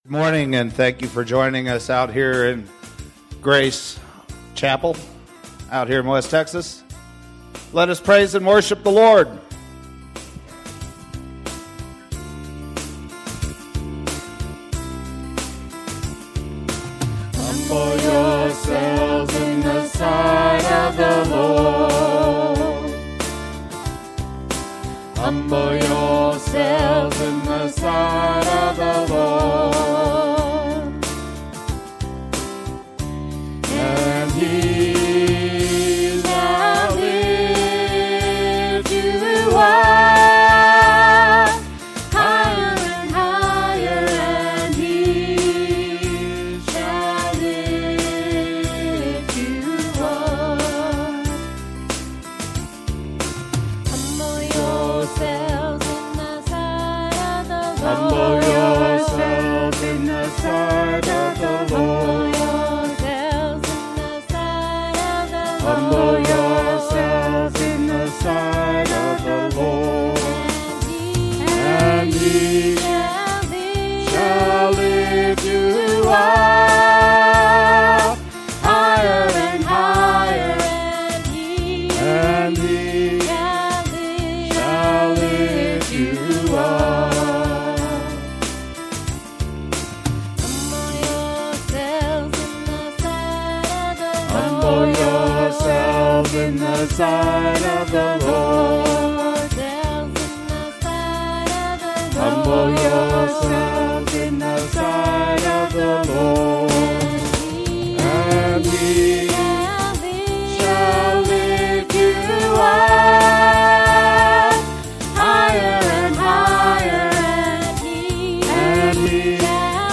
Sunday Texas Church Service 02/22/2015 | The Fishermen Ministry